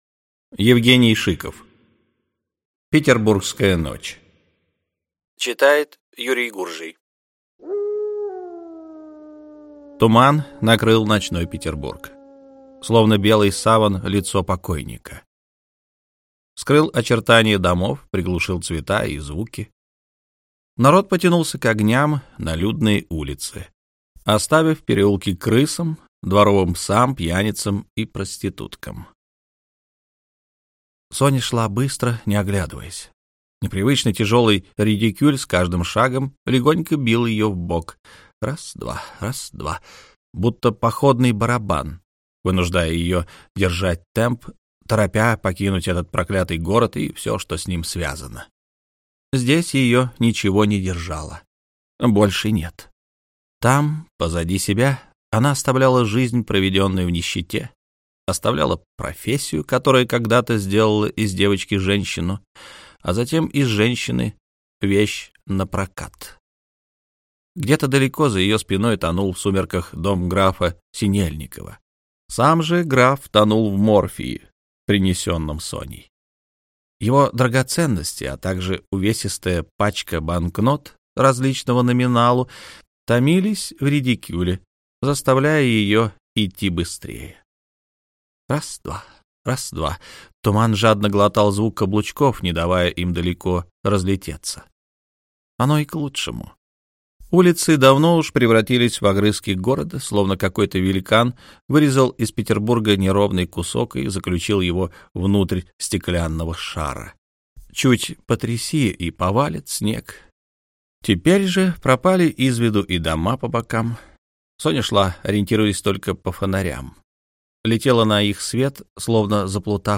Аудиокнига Инсаров 4. Петербургская псарня | Библиотека аудиокниг